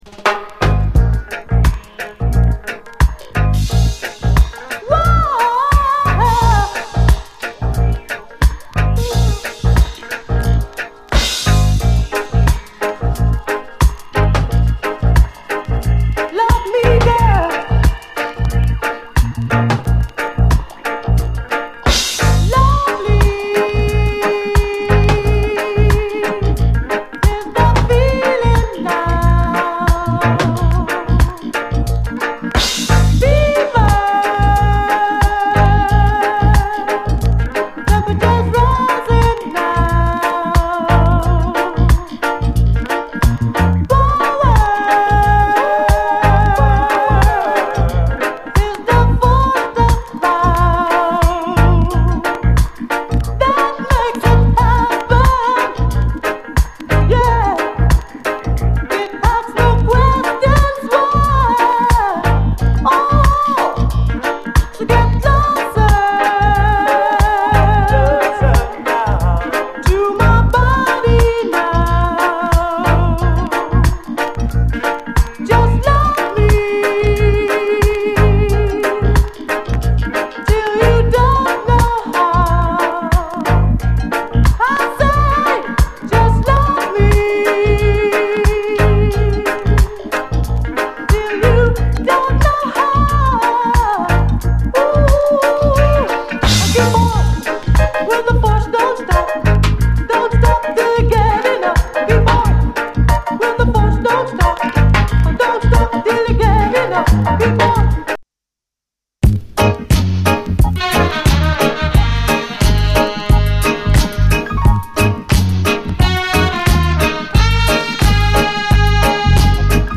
DISCO, REGGAE